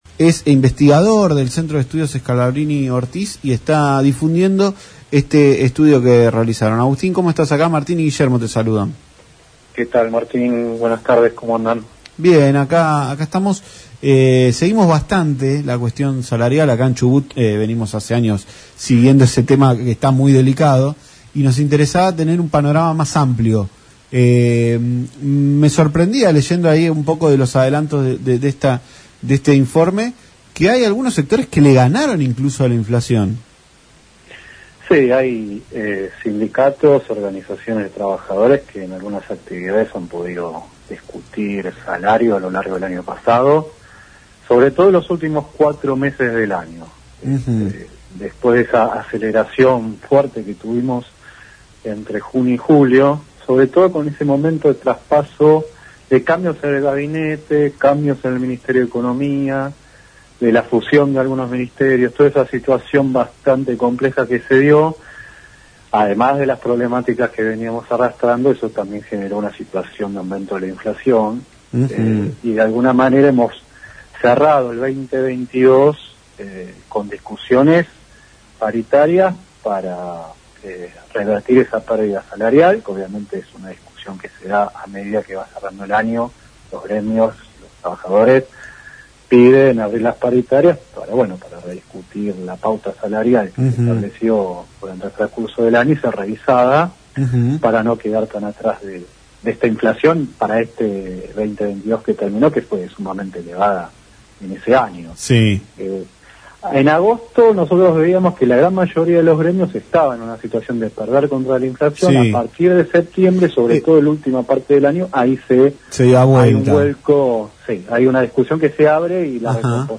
Por este motivo, el radatilense de 23 años dialogó con Tarde Para Miles por LaCienPuntoUno para dar detalles de este film.